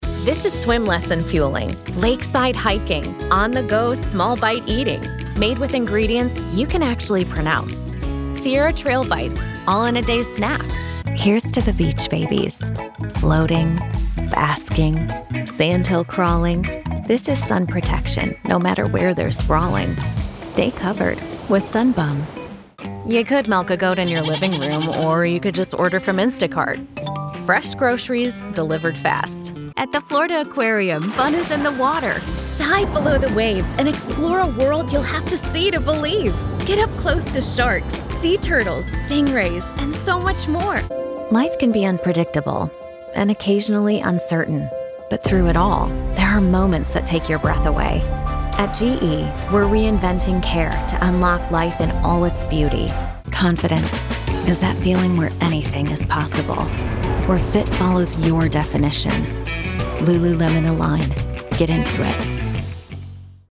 Child, Teenager, Young Adult, Adult, Mature Adult
Has Own Studio
ANIMATION 🎬
COMMERCIAL 💸